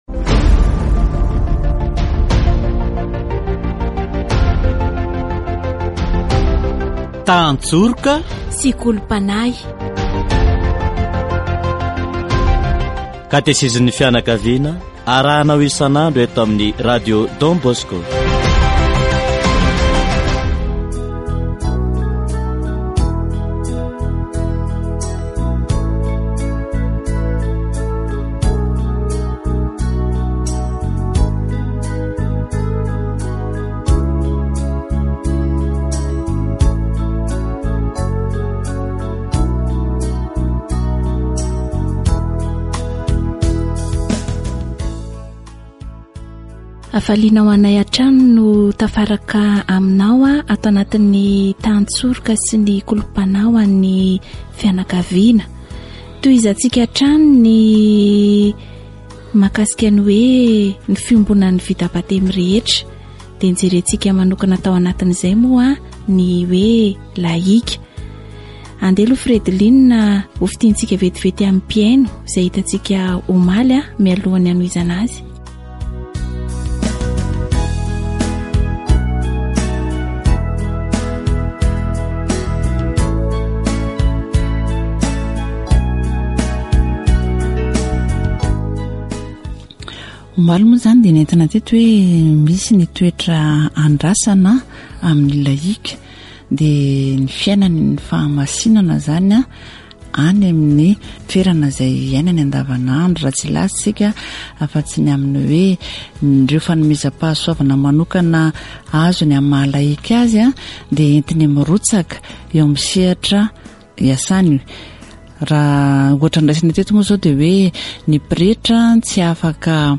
Catéchèse sur la communion des baptisés